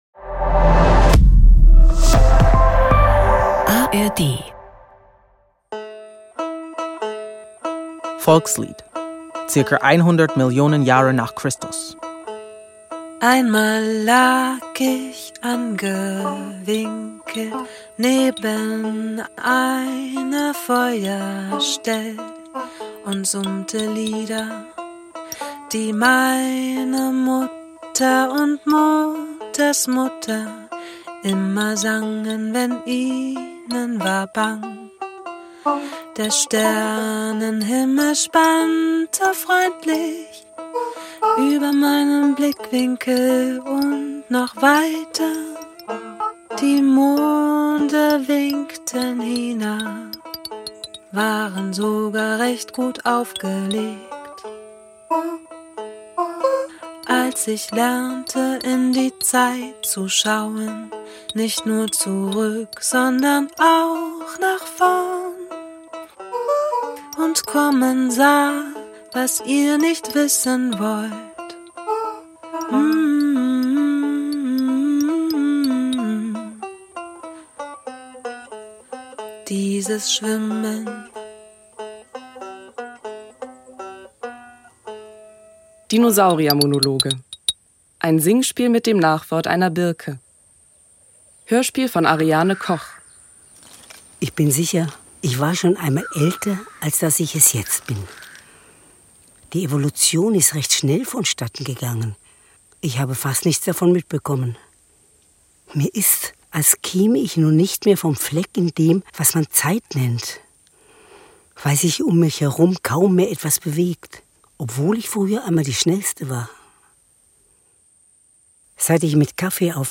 Dinosauriermonologe ~ Nothing Serious - ARD Comedy-Hörspiele Podcast